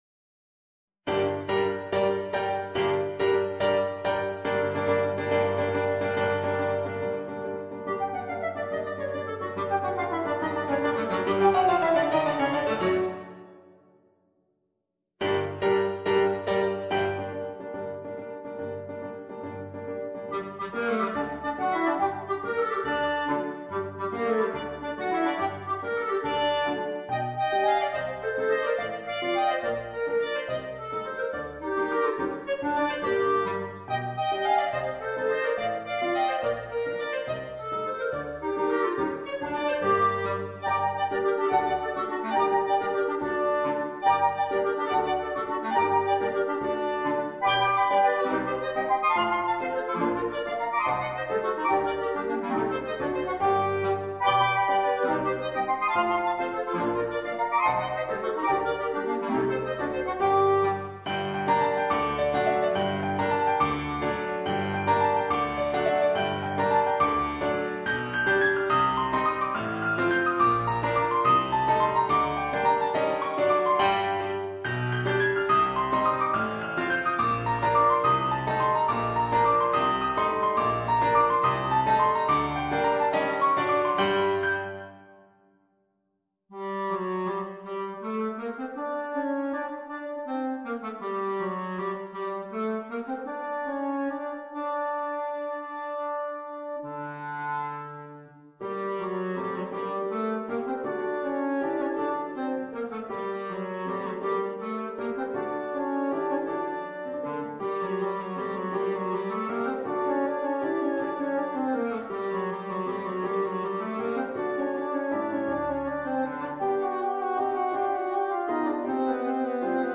per 2 clarinetti e pianoforte
in forma di saltarello